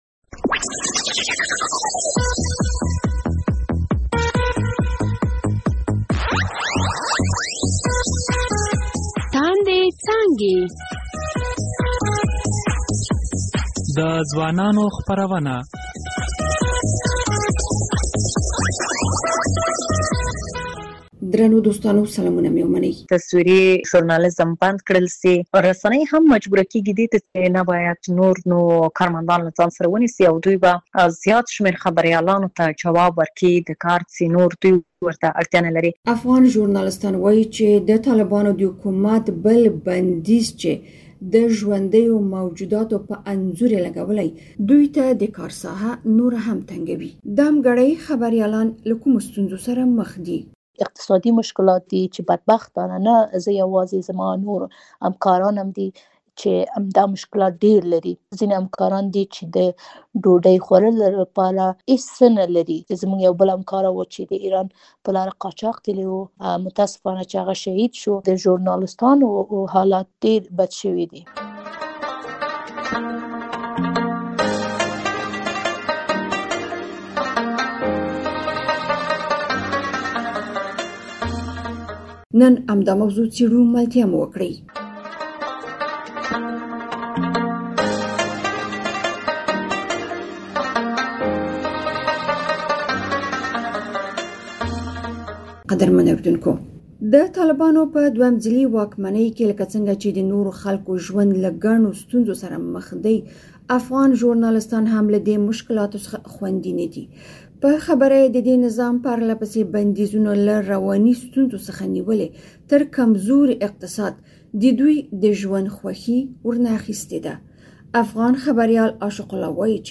د ازادي راډیو په اوونیزې تاندې څانګې خپرونه کې د هغو خبریالانو خبرې اورئ چې وایي، د طالبانو د نظام نوی بندیز چې په رسنیو کې یې د ژوندیو موجوداتو د انځور په خپرولو لګولی، دوی ته یې د کار ساحه نوره هم تنګه کړې.